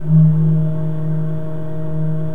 Index of /90_sSampleCDs/Propeller Island - Cathedral Organ/Partition H/KOPPELFLUT M